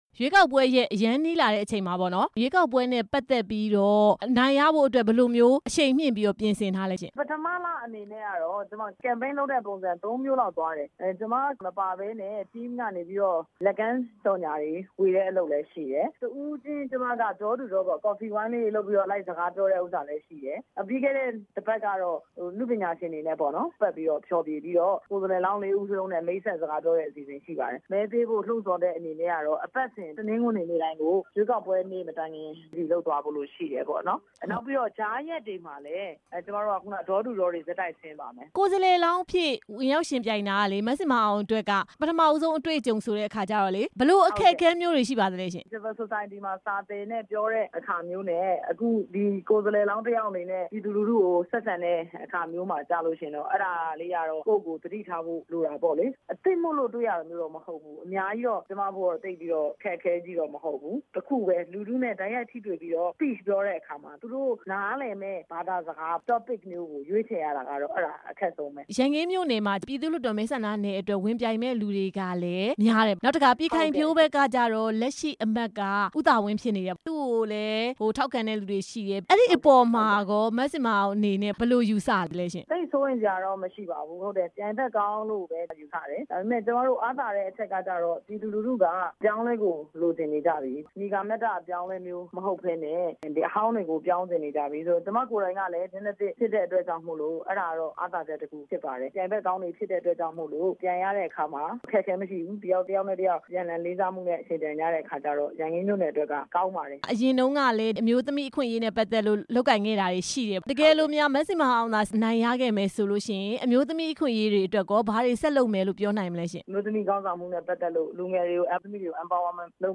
မဲစာရင်းမှားယွင်းမှု NLD ကိုယ်စားလှယ်လောင်း မဇင်မာအောင်နဲ့ မေးမြန်းချက်